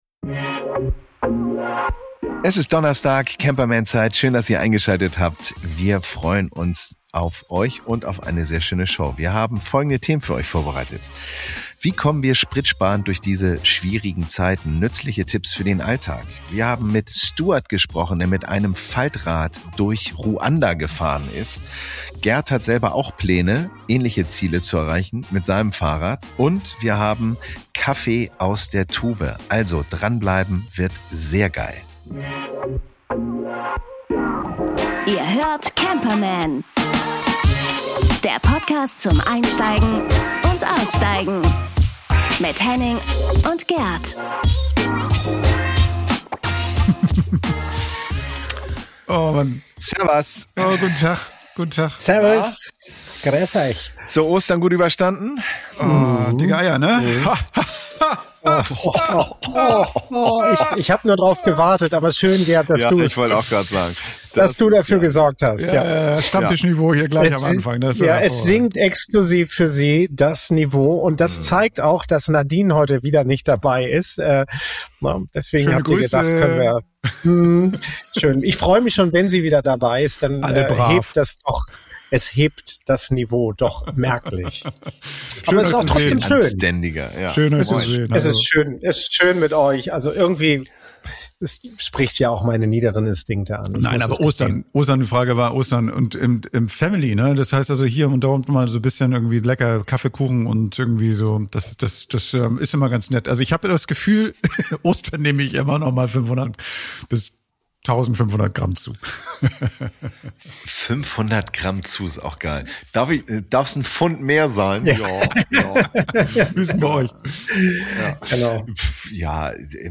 ASMR pur: Eine Live-Verköstigung bringt den Genuss aufs Ohr.